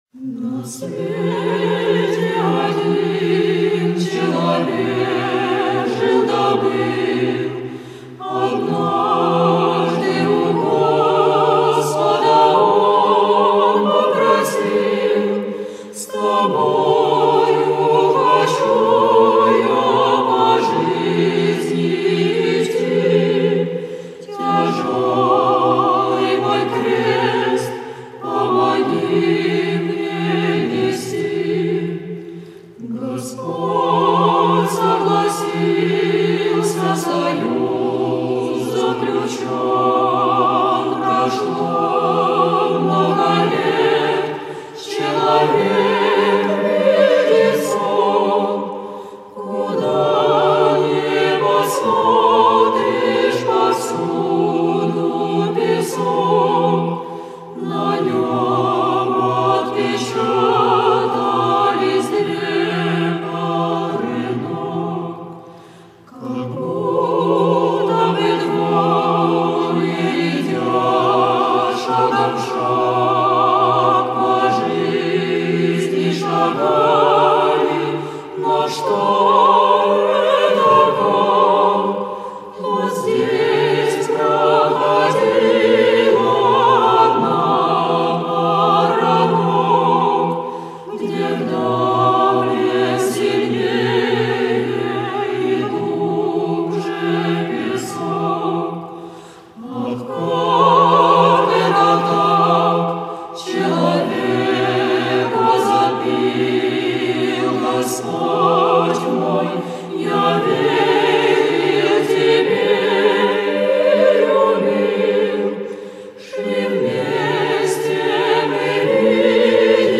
Песни на стихотворение:
1. «Хор храма Андрея Первозванного г.Вологда – притча Следы» /